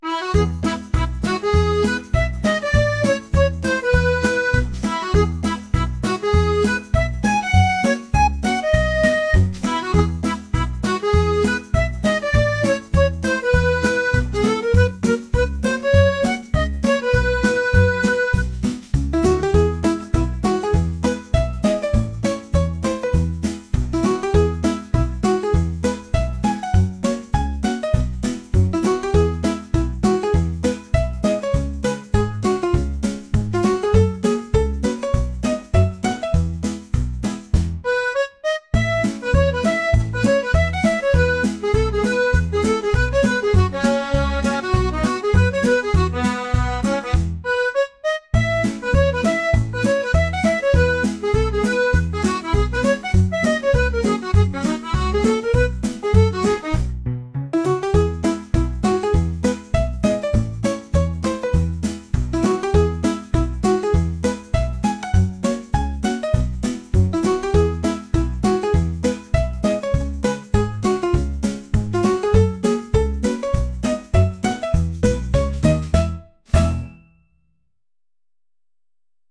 晴れた日にのんびり洗濯物を干して、猫と戯れて遊ぶようなアコーディオンの音楽です。